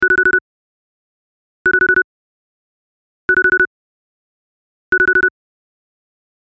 Download Cell Phone sound effect for free.
Cell Phone